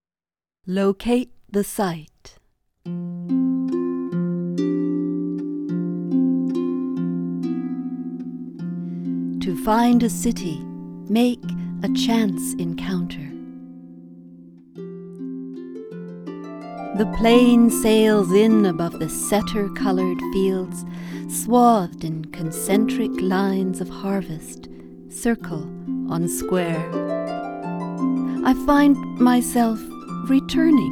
Locate the site—performed with accompaniment